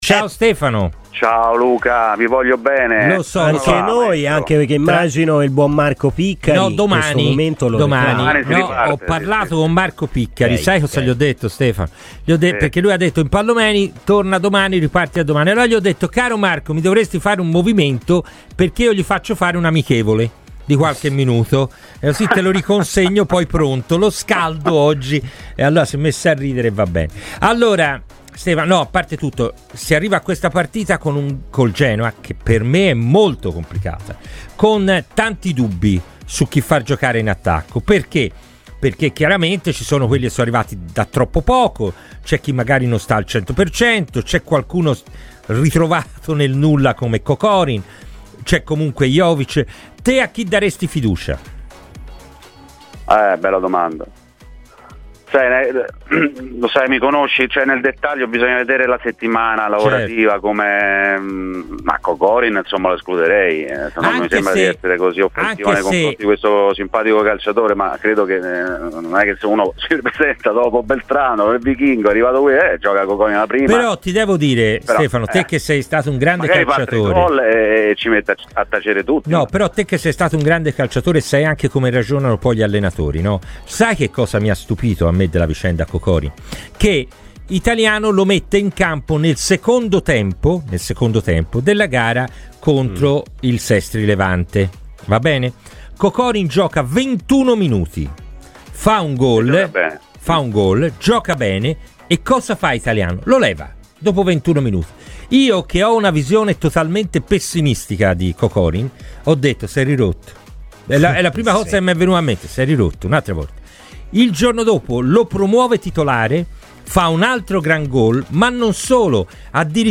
ex calciatore e attuale opinionisti, nel corso di “Palla al Centro” su Radio FirenzeViola ha parlato così di Fiorentina